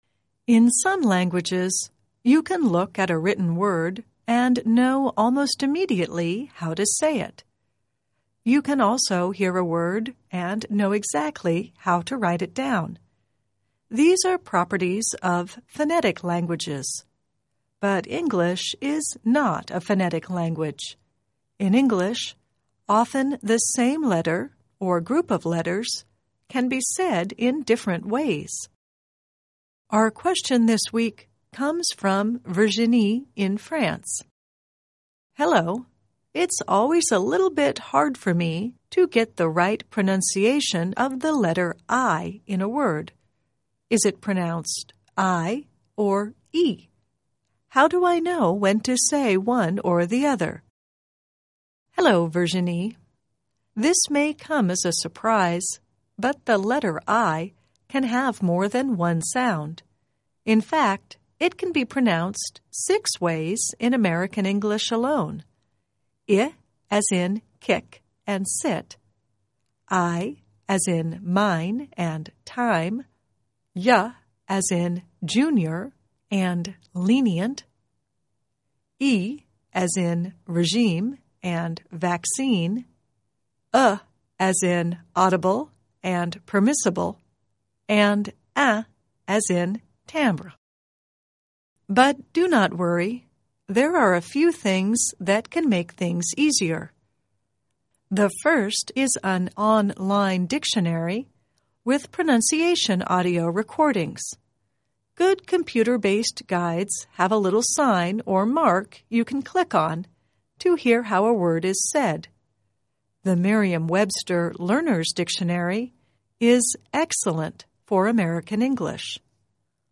The letter -i can have more than one sound. In fact, it can be pronounced six ways in American English alone!